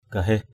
/ɡ͡ɣa-he̞h/ (d.) thường dân (đối với giai cấp giáo sĩ) = gens ordinaires.